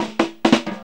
FILL 1   110.wav